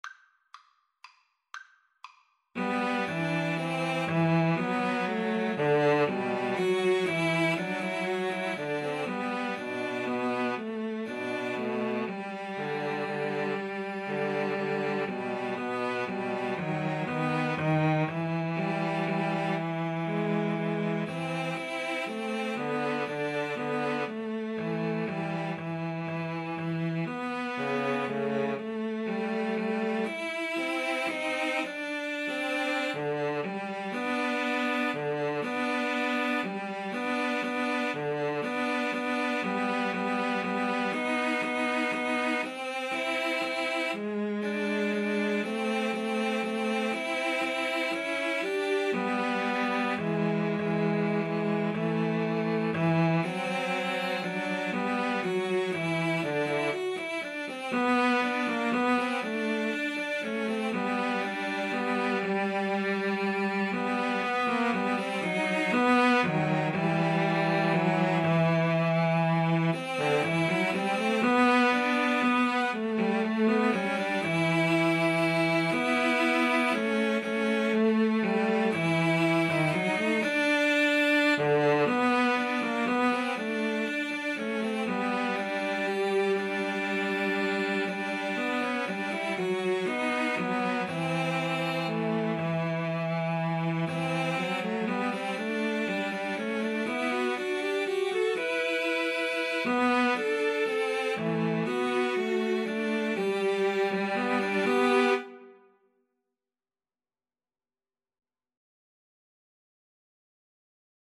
3/4 (View more 3/4 Music)
= 120 Tempo di Valse = c. 120
Cello Trio  (View more Intermediate Cello Trio Music)